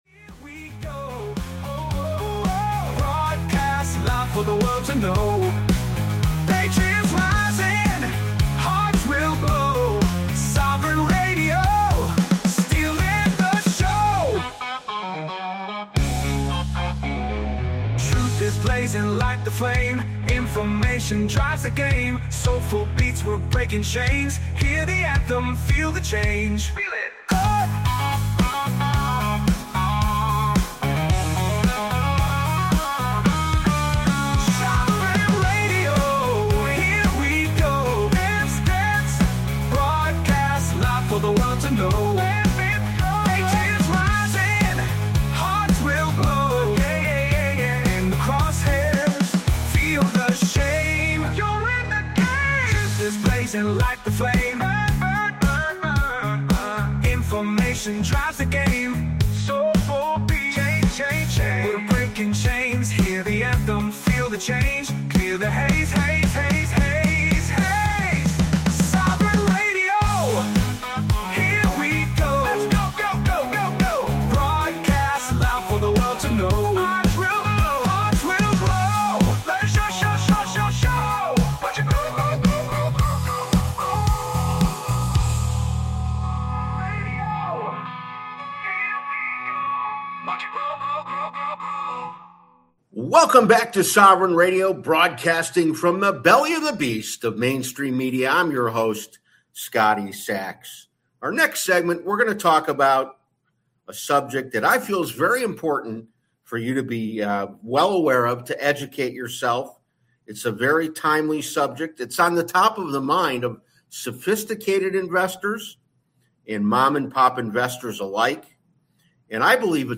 This radio show, Sovereign Radio